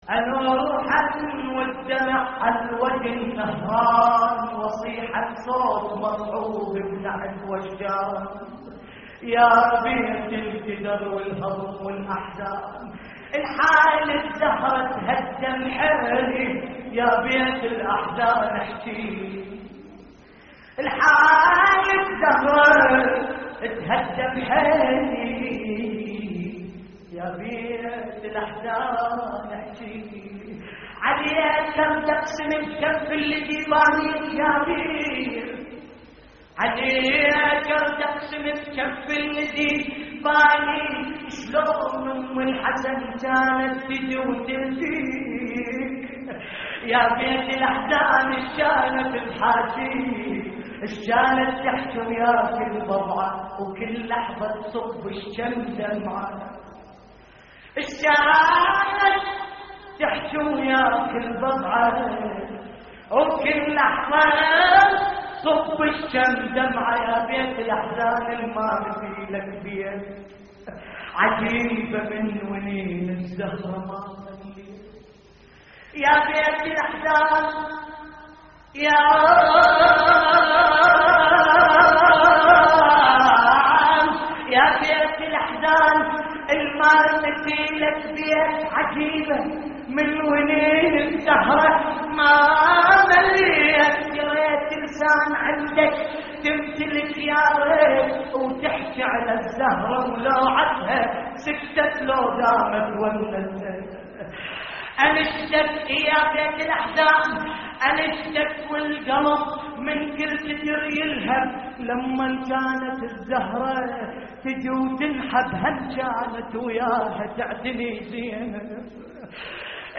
نعي